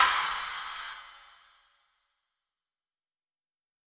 PSPLASH.wav